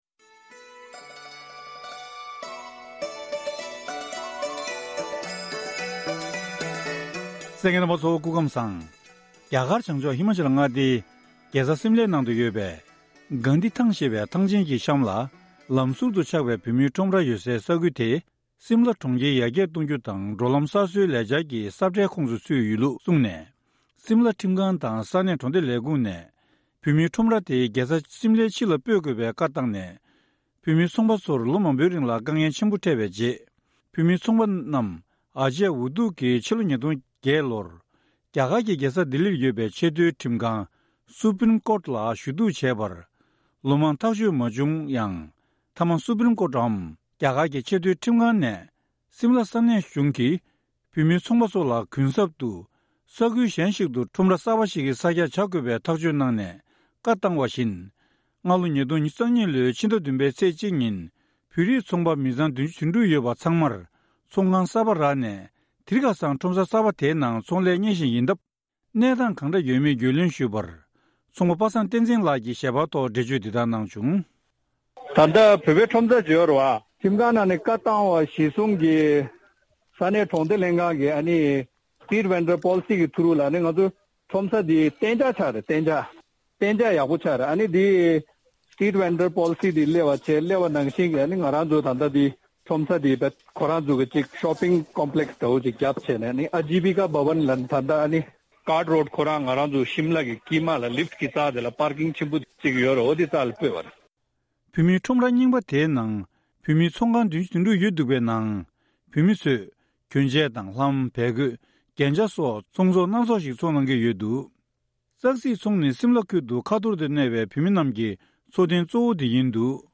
བཀའ་འདྲི་ཕྱོགས་སྒྲིག་ཞུས་པ་ཞིག་གསན་རོགས་གནང་།